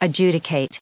140_adjudicate.ogg